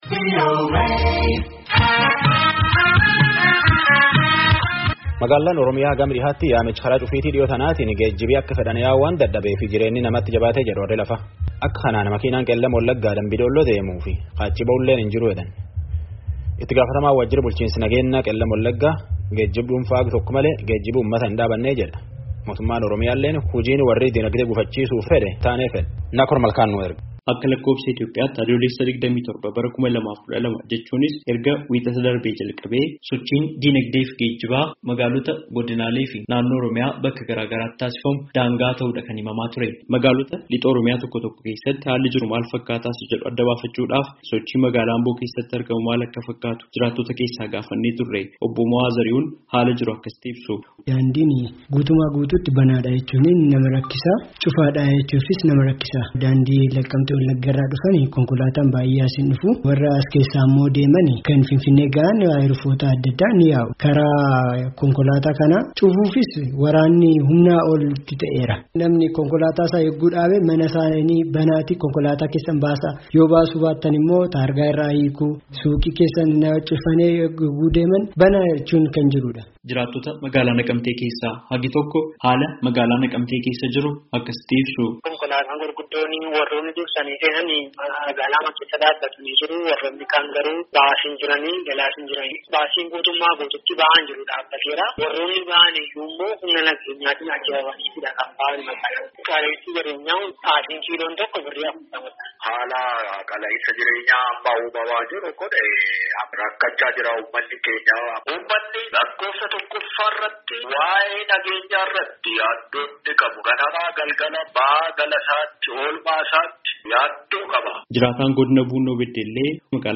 Magaalaalee Oromiyaa Lixaa tokko tokko keessatti sochiin geejjibaa waan gufateef qaala’insa jireenyaaf isaan saaxiluu jiraattotni VOAf yaada kennan dubbatan.
Gabaasaa guutuu caqasaa.